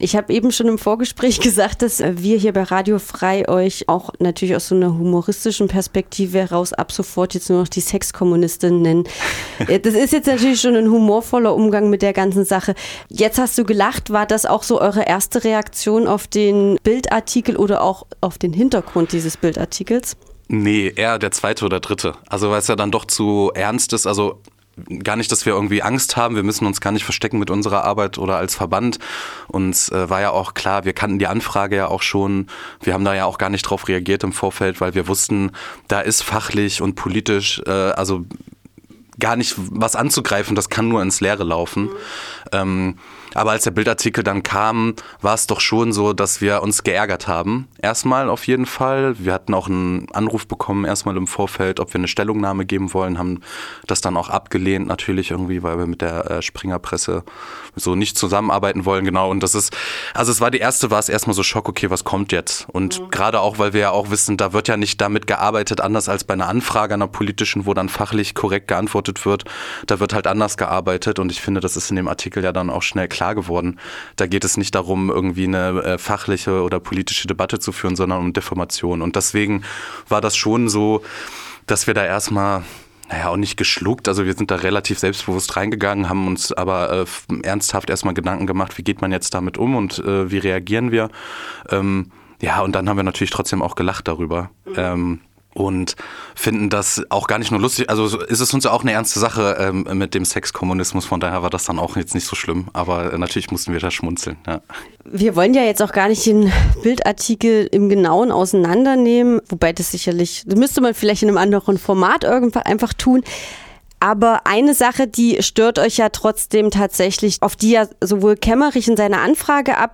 �Kein Euro, den wir bekommen, ist verschwendet, sondern sehr gut investiert.� | Interview mit den Falken